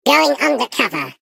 Sfx_tool_spypenguin_vo_enter_04.ogg